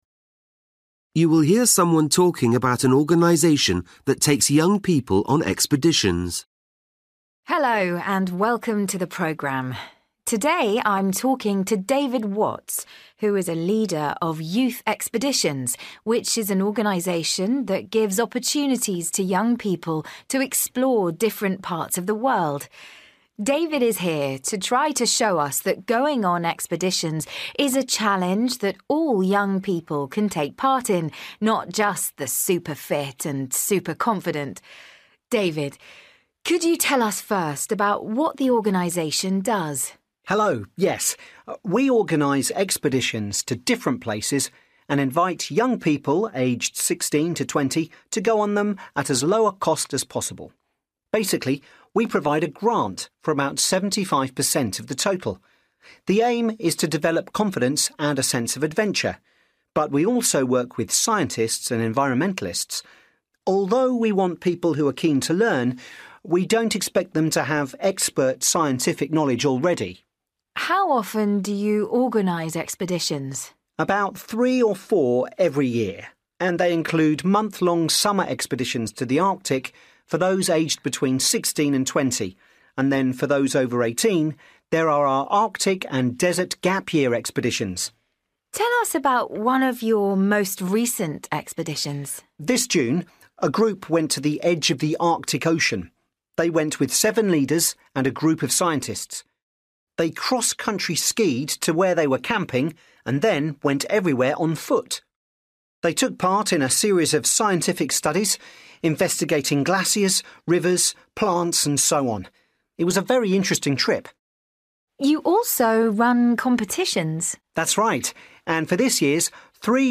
You will hear someone talking about an organization that takes young people on expeditions.